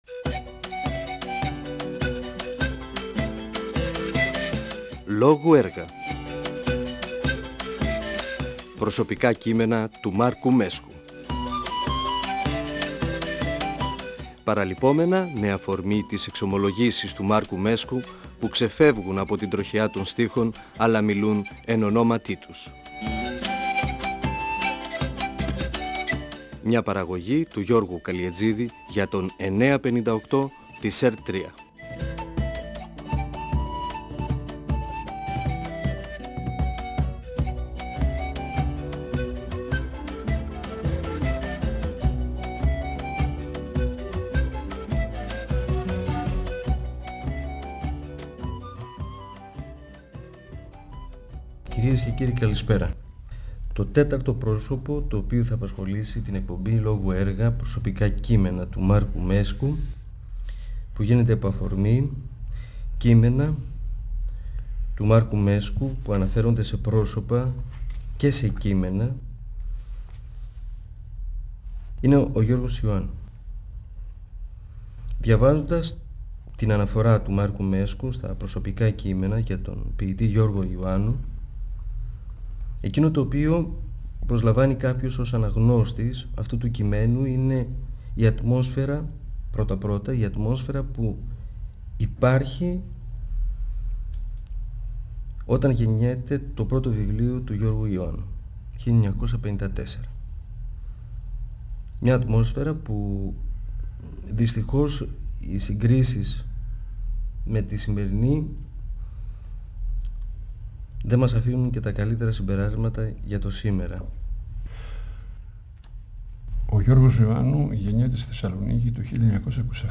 Ο ποιητής και δοκιμιογράφος Μάρκος Μέσκος (1935-2019) μιλά για το ποιητικό έργο του Γιώργου Ιωάννου (εκπομπή 1η) και για τα ιδιαίτερα χαρακτηριστικά τής ποίησής του. Αναφέρεται στα λογοτεχνικά περιοδικά τής Θεσσαλονίκης (Διαγώνιος, Κοχλίας, Κριτική, Νέα Πορεία) και στους λογοτέχνες που τα διηύθυναν.
Αφορμή για τη συζήτηση με τον Μάρκο Μέσκο στάθηκε το βιβλίο του «Προσωπικά κείμενα» (εκδ. Νεφέλη, 2000).ΦΩΝΕΣ ΑΡΧΕΙΟΥ του 958fm της ΕΡΤ3.